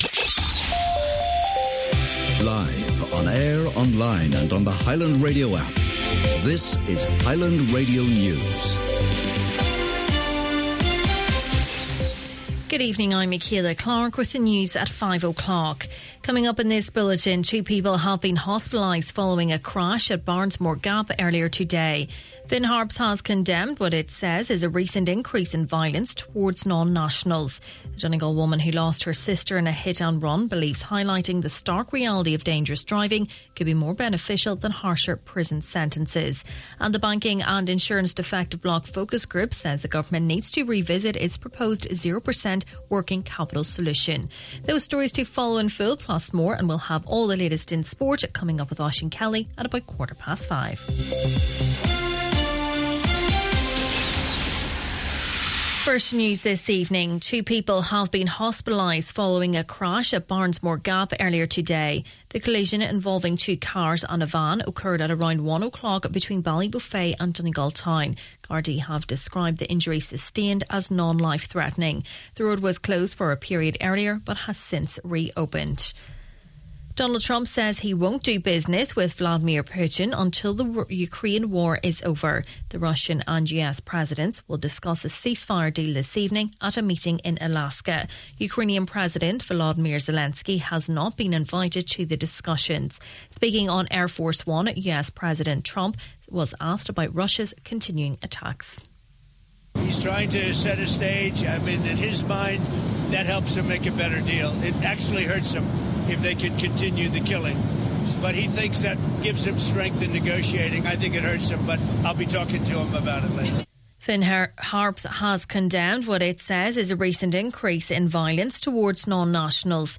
Main Evening News, Sport and Obituaries – Friday, August 15th